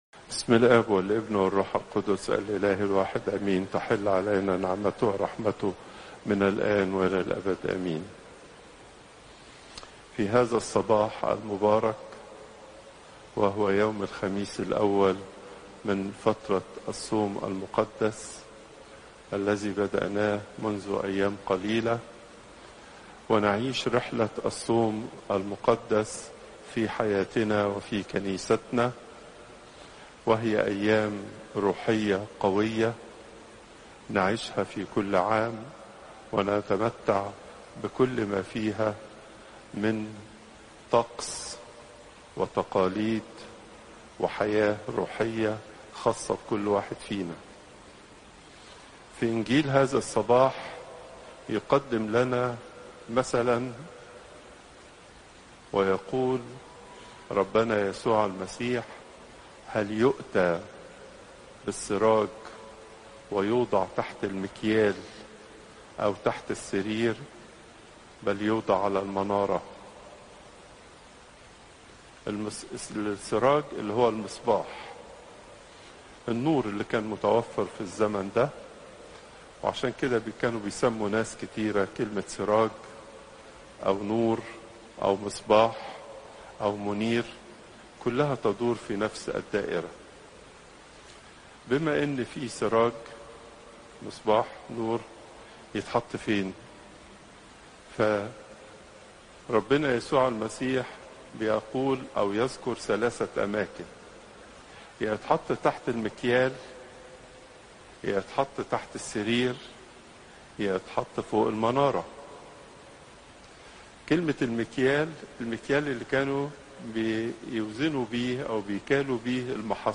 Popup Player تحميل الصوت البابا تواضروس الثانى الخميس، 27 فبراير 2025 24:22 المحاضرة الأسبوعية لقداسة البابا تواضروس الثاني الزيارات: 234